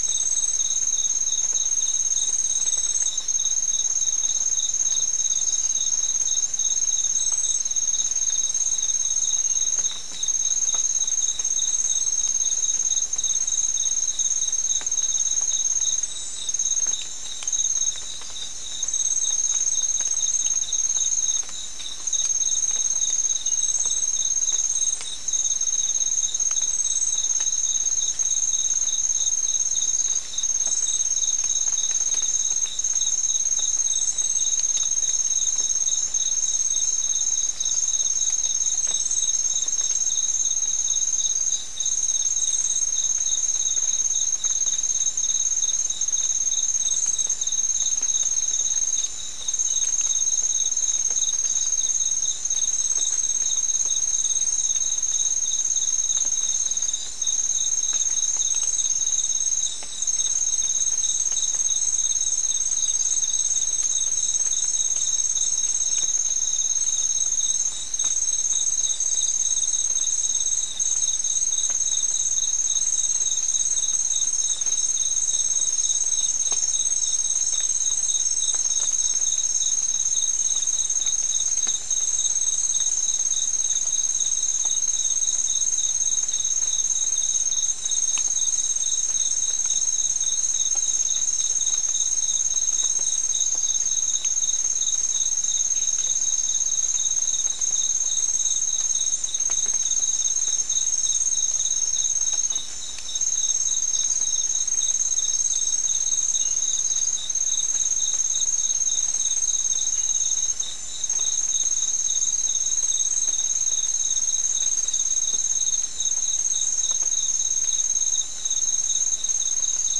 Non-specimen recording: Soundscape Recording Location: South America: Guyana: Mill Site: 3
Recorder: SM3